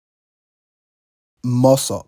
MOSOP, pronounced moh-SOP) and presented the Nigerian government with the Ogoni Bill of Rights, which included requests to preside over their own political affairs within Nigeria, parlay more economic resources into Ogoni development, restore the health of their people, and protect the environment from further damage.